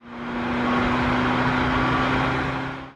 ogg / general / highway / oldcar / tovertake7.ogg